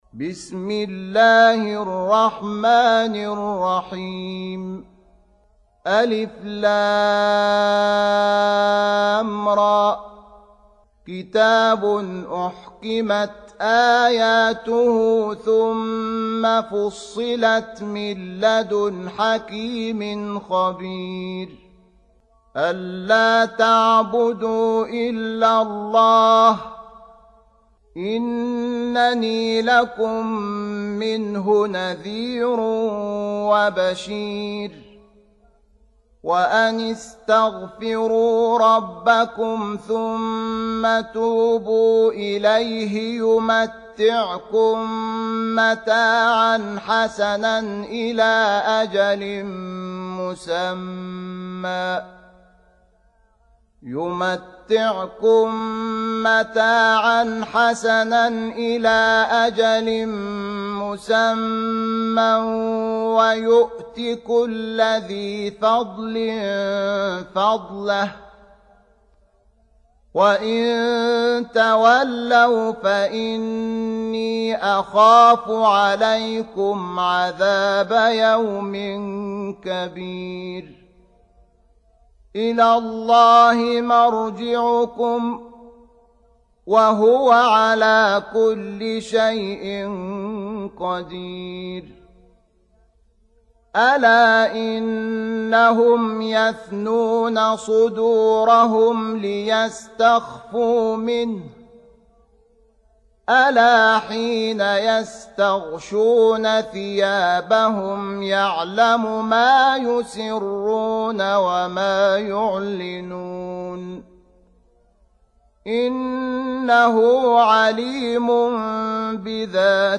11. Surah H�d سورة هود Audio Quran Tarteel Recitation
Surah Repeating تكرار السورة Download Surah حمّل السورة Reciting Murattalah Audio for 11.